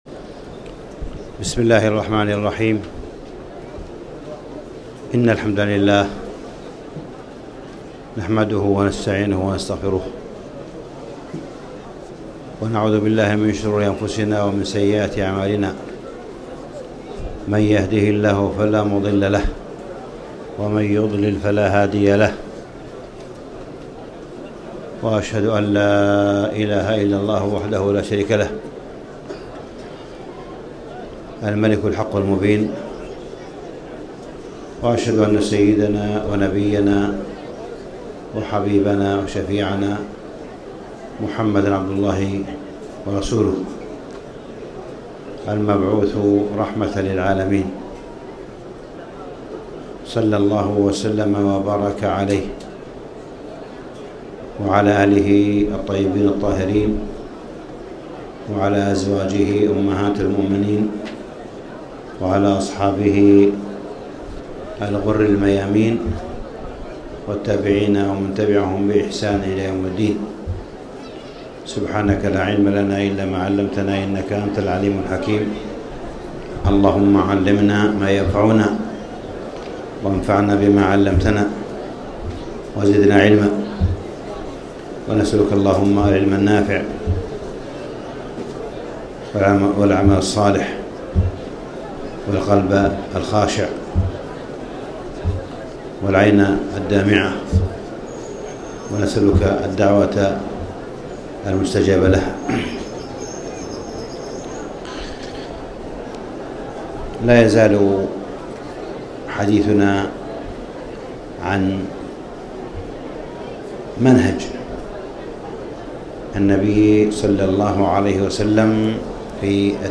تاريخ النشر ١٨ رمضان ١٤٣٨ هـ المكان: المسجد الحرام الشيخ: معالي الشيخ أ.د. صالح بن عبدالله بن حميد معالي الشيخ أ.د. صالح بن عبدالله بن حميد هدي النبي صلى الله عليه وسلم مع غير المسلمين